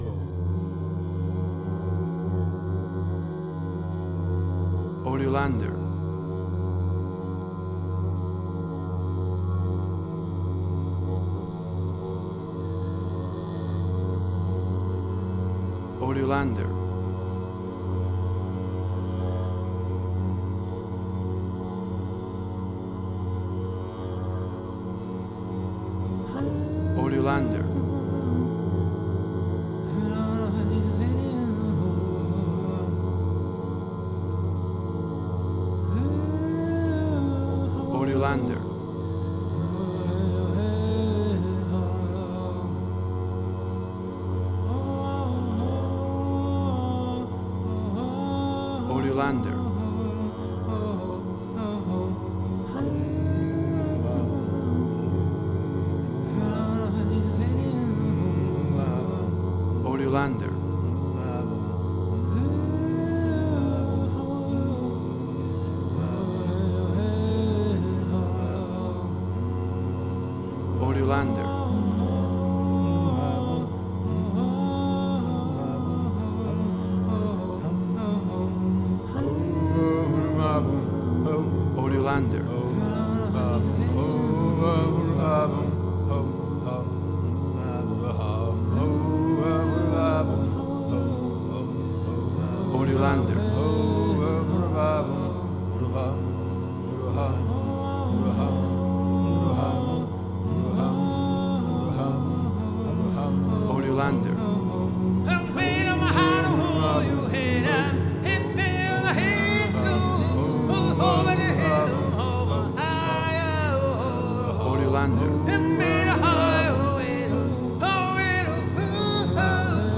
emotional music
WAV Sample Rate: 16-Bit stereo, 44.1 kHz